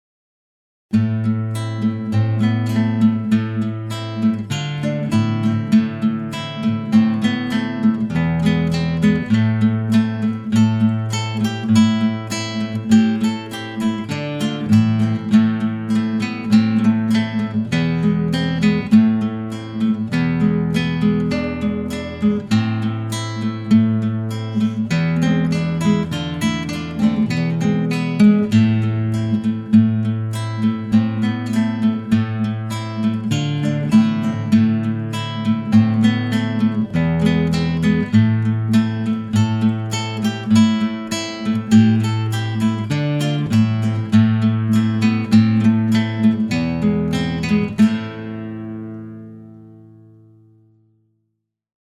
Gitarre, akustische Gitarre
Klassischer Stil